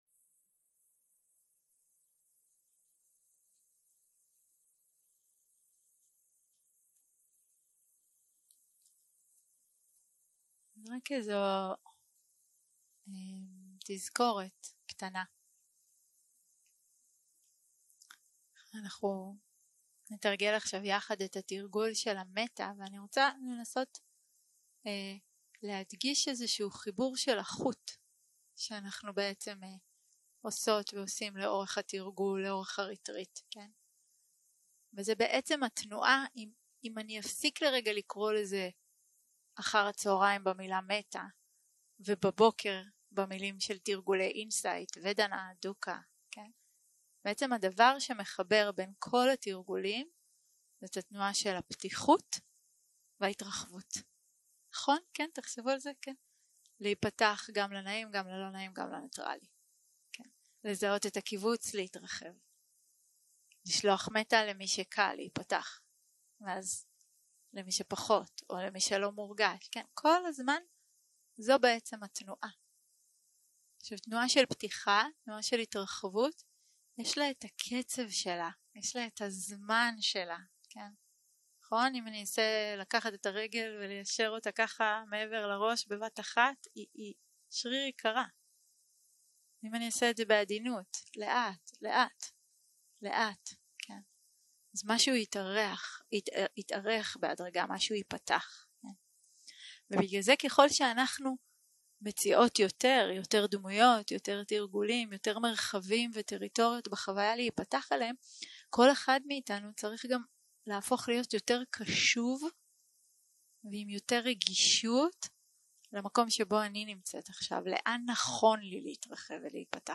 יום 5 - הקלטה 17 - צהרים - מדיטציה מונחית - מטא - כל הקטגוריות
סוג ההקלטה: מדיטציה מונחית